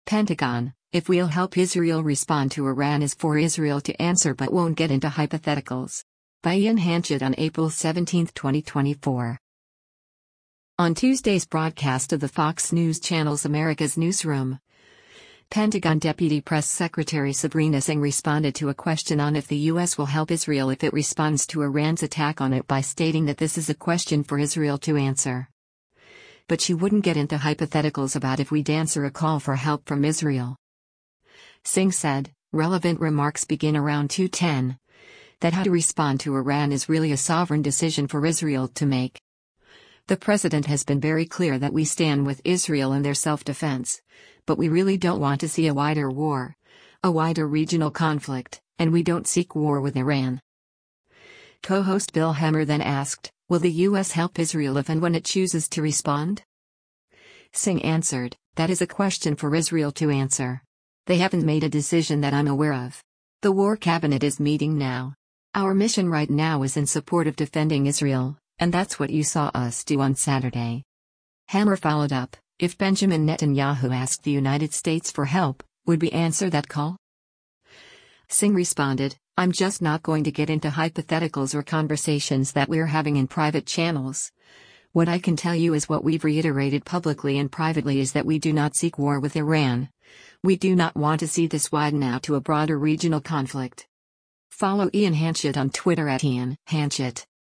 On Tuesday’s broadcast of the Fox News Channel’s “America’s Newsroom,” Pentagon Deputy Press Secretary Sabrina Singh responded to a question on if the U.S. will help Israel if it responds to Iran’s attack on it by stating that this “is a question for Israel to answer.” But she wouldn’t “get into hypotheticals” about if we’d answer a call for help from Israel.
Co-host Bill Hemmer then asked, “Will the U.S. help Israel if and when it chooses to respond?”